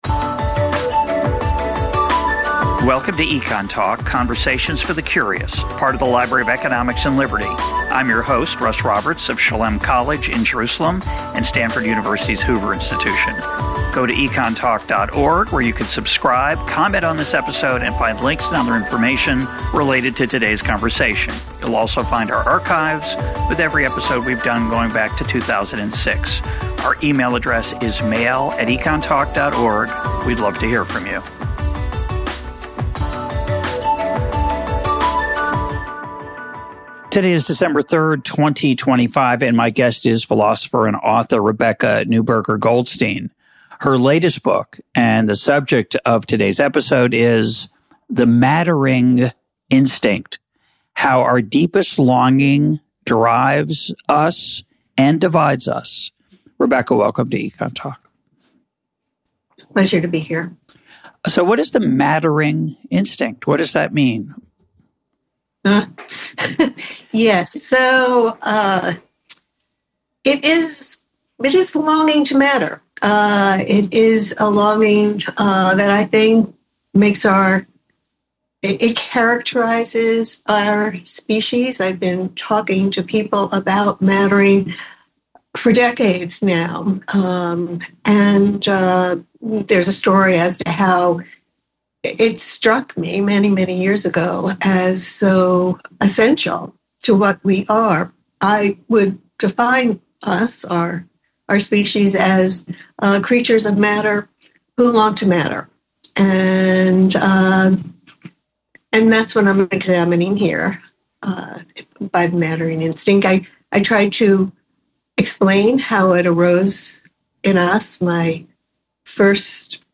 Philosopher and author Rebecca Newberger Goldstein discusses her new book, The Mattering Instinct, which argues that our lives are a quest to validate our inherent self-centeredness. Tracing this essential longing from physics and biology through to ethics and politics, she explains to EconTalk's Russ Roberts why material success alone can never satisfy our deep-seated need to matter.